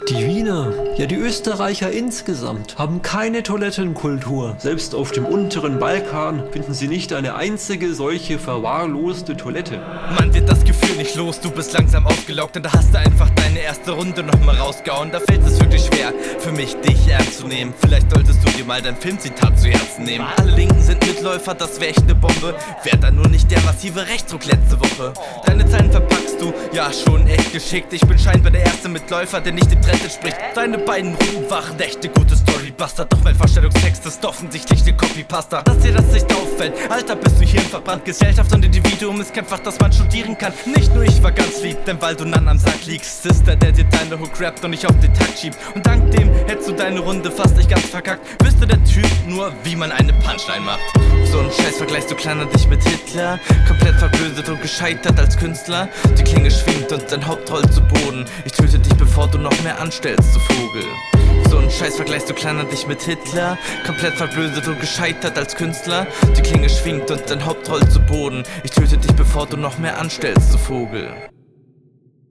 Sehe dich hier flowlich und inhaltlich überlegen.